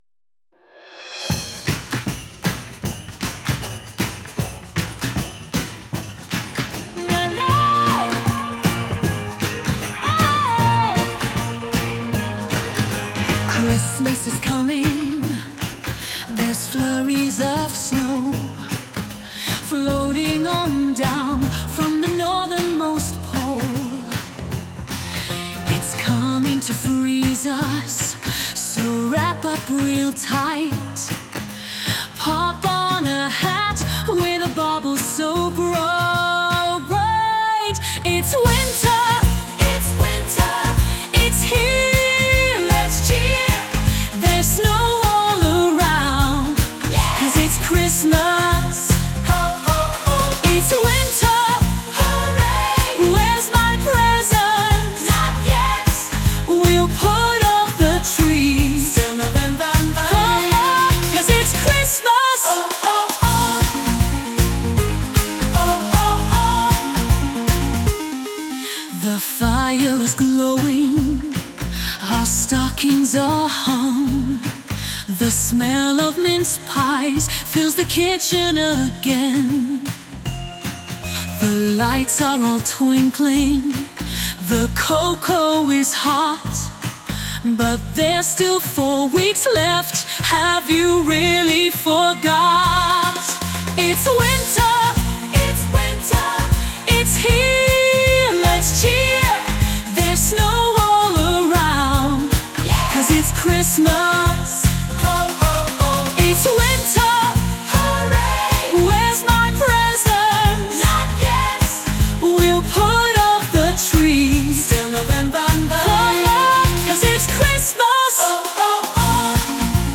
Eurovision version